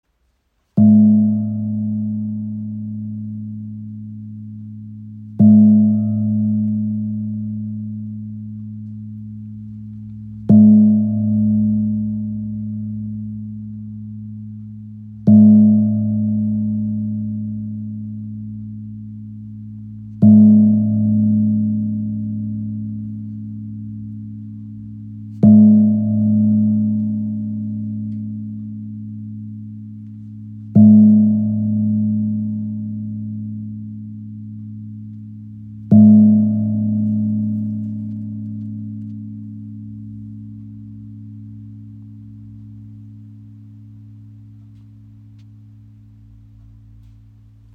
Buckelgong aus Burma – Herzklang der alten Welt | ø 55 cm | ~ Ab 2
• Icon Tiefer, klarer Klang mit zentrierender Wirkung – ideal für Meditation
Der Buckelgong aus Burma trägt den Herzschlag einer alten Klangtradition in sich. Handgehämmert aus Bronze, entfaltet er beim Anschlag seines Mittelpunkts einen tiefen, klaren Ton – sanft, erdend, durchdringend. Sein Klang wirkt sammelnd, beruhigend und lädt dazu ein, innerlich still zu werden.
Dort angeschlagen, entfaltet sich ein tiefer, klarer Ton – wie eine Stimme aus der Tiefe.